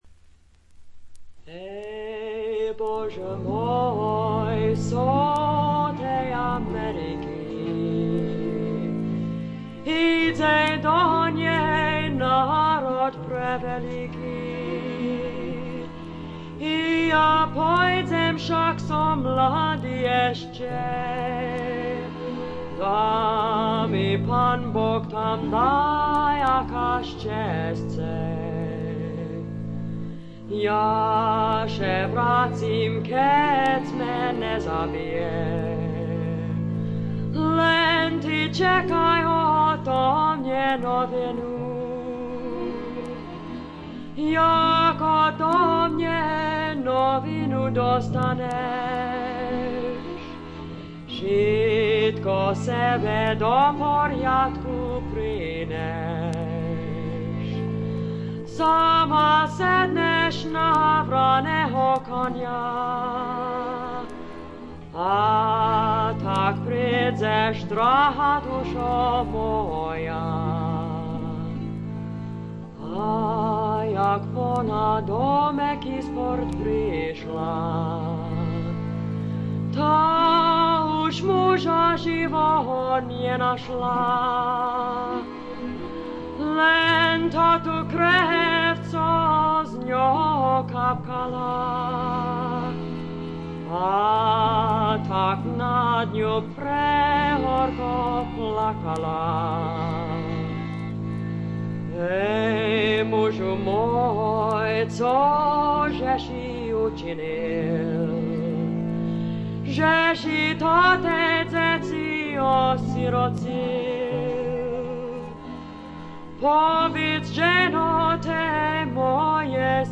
軽微なバックグラウンドノイズにチリプチ少し。
魅力的なヴォイスでしっとりと情感豊かに歌います。
試聴曲は現品からの取り込み音源です。
Recorded At - WDUQ, Pittsburgh, PA